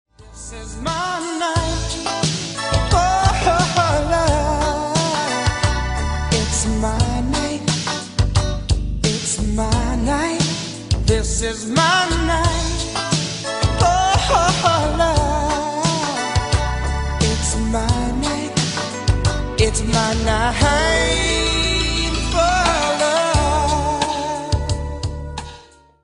RnB & Garage
Classic R&B